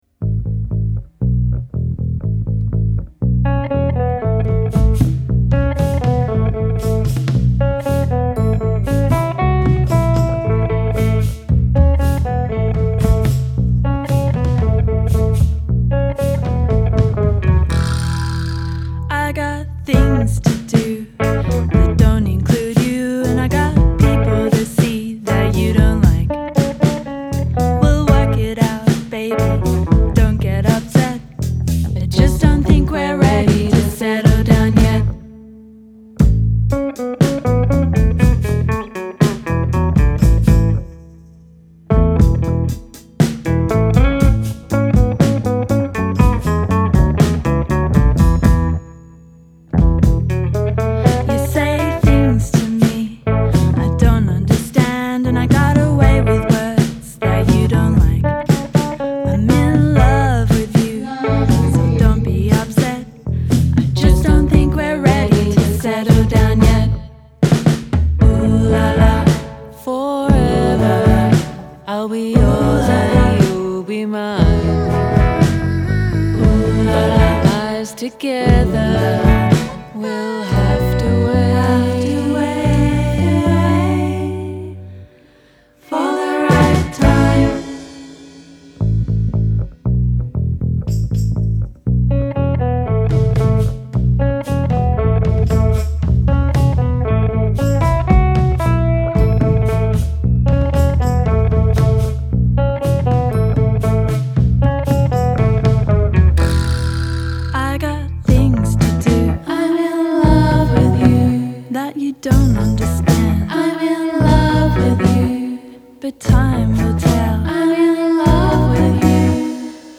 quartetto tutto femminile proveniente da Melbourne